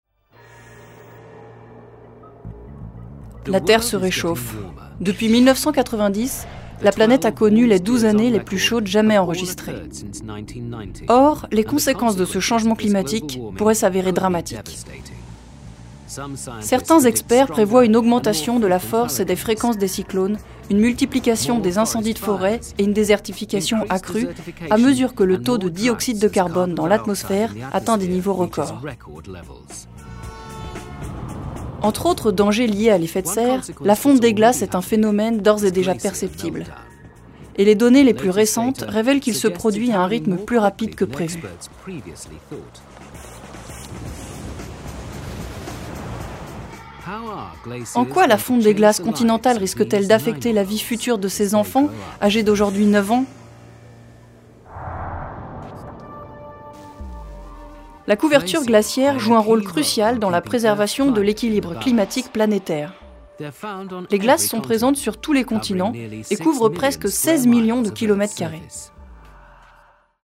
Documentaire « La fonte des glaces »
Des voix-off